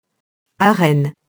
arène [arɛn]